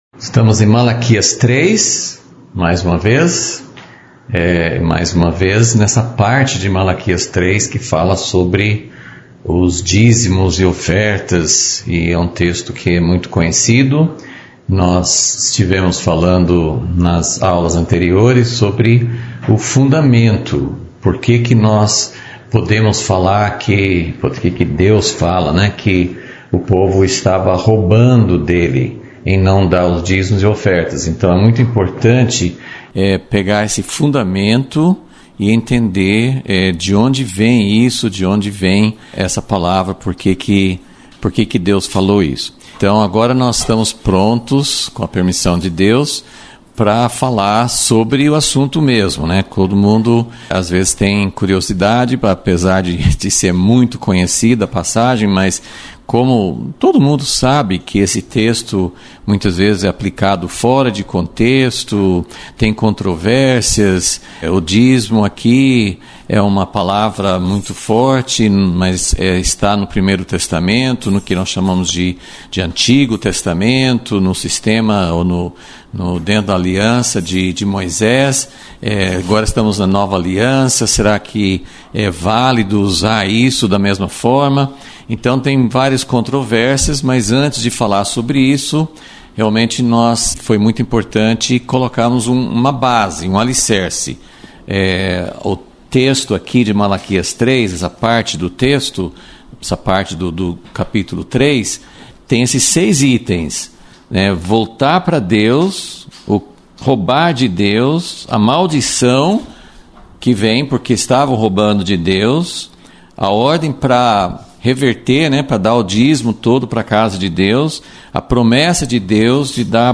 Aula 19 – Vol. 37 – O que não estamos entendendo sobre o Dízimo